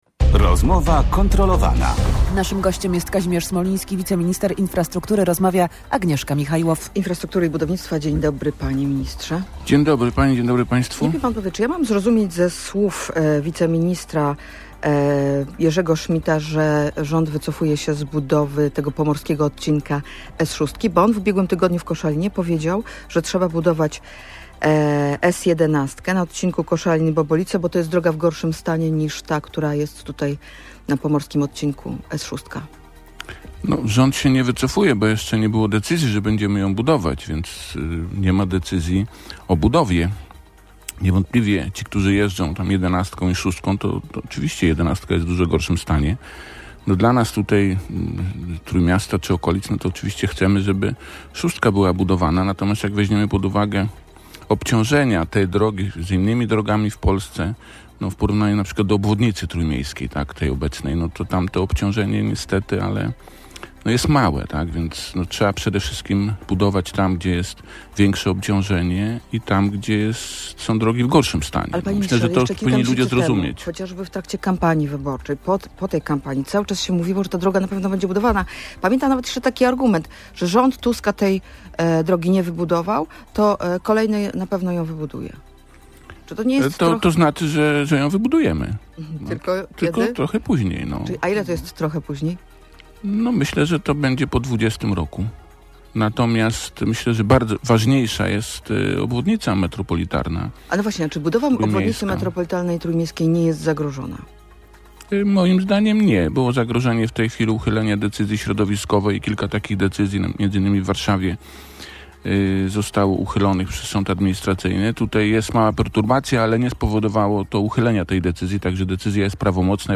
– Jak weźmiemy pod uwagę obciążenie tej drogi w porównaniu do innych w kraju, to jest małe. Trasę S6 wybudujemy, tylko w innym terminie – mówił w Radiu Gdańsk Kazimierz Smoliński, wiceminister infrastruktury i budownictwa. W ten sposób odniósł się do informacji, że rząd wycofuje się z budowy jednej z najważniejszych tras na Pomorzu.